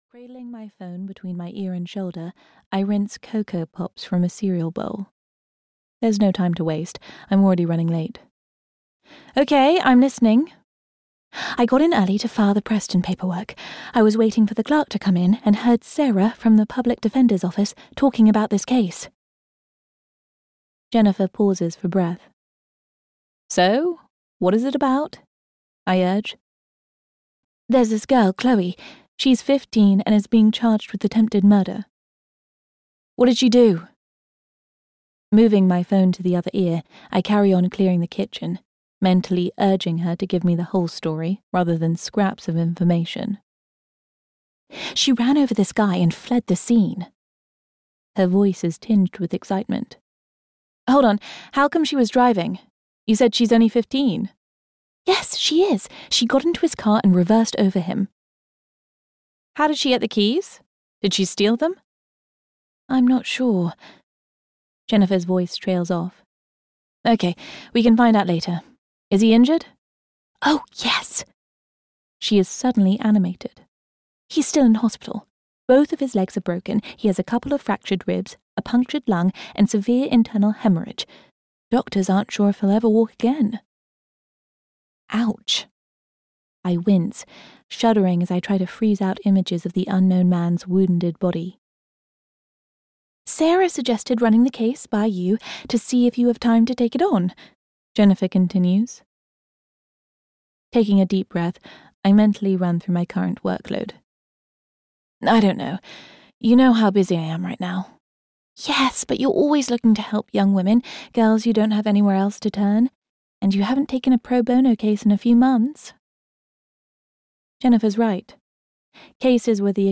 If You Only Knew (EN) audiokniha
Ukázka z knihy